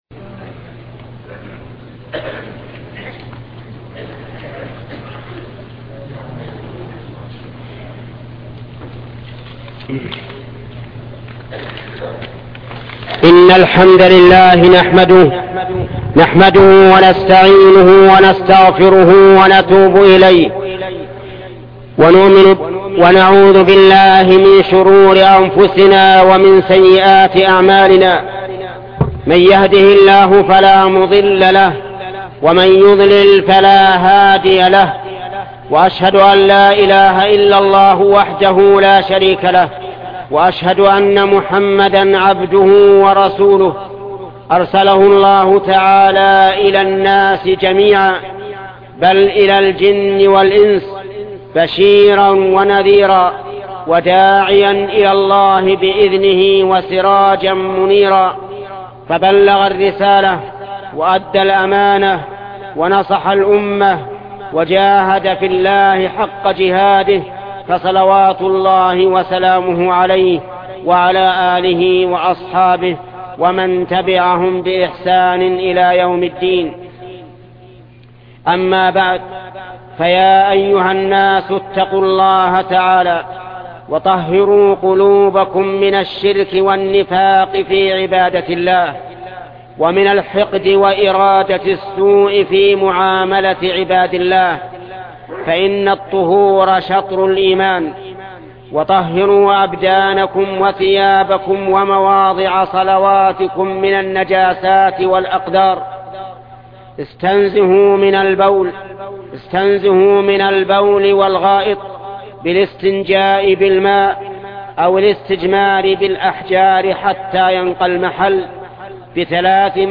خطبة طهروا أبدانكم - العلم الشيخ محمد بن صالح العثيمين